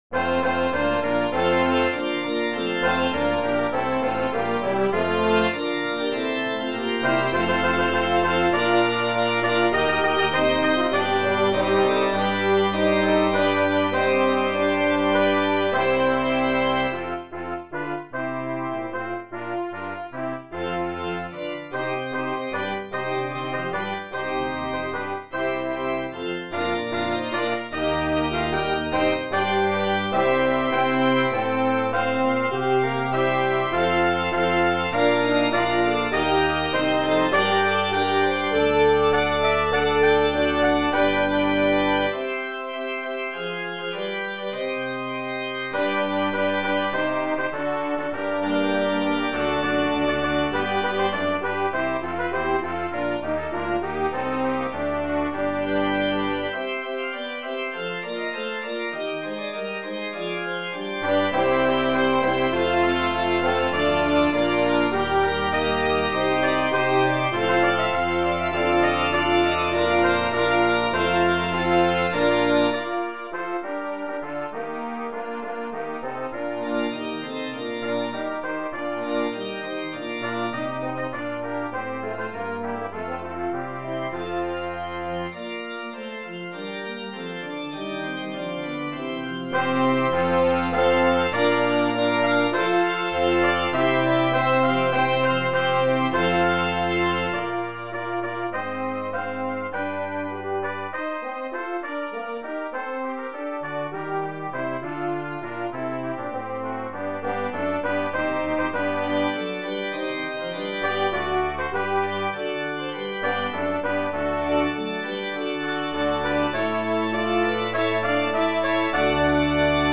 Brass Quintet with Organ